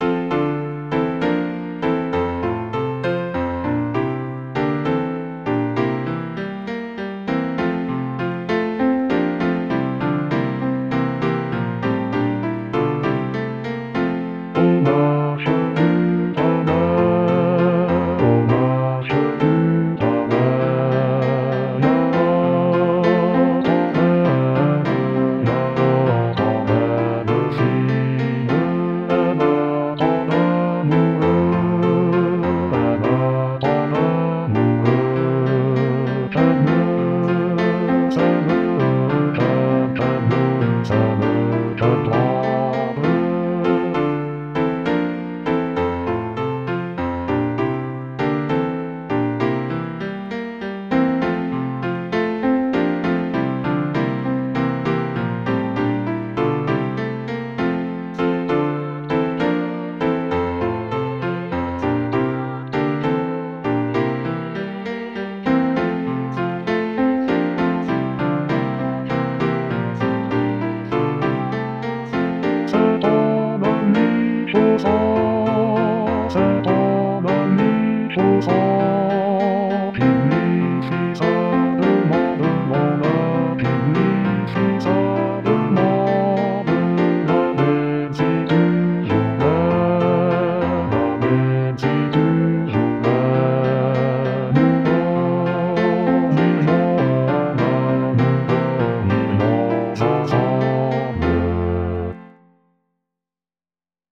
basse-Aux-marches-du-palais.mp3